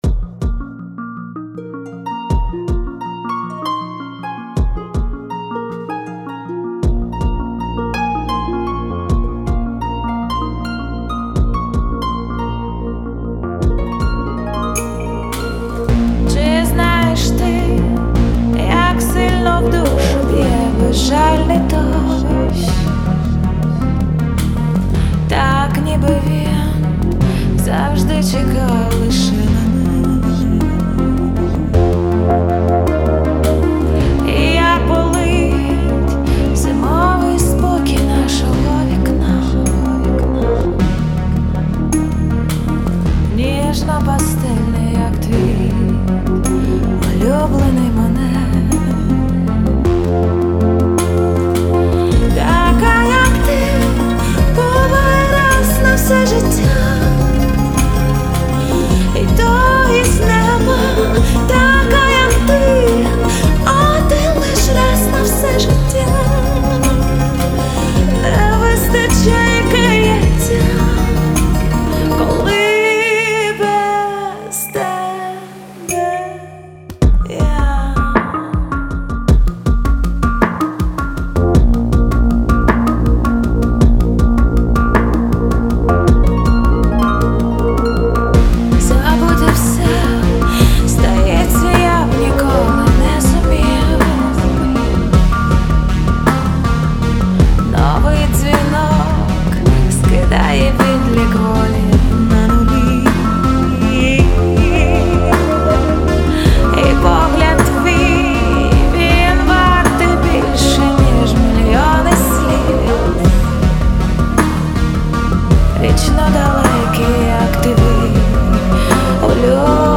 кавер версію